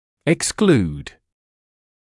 [ɪks’kluːd][икс’клуːд]исключать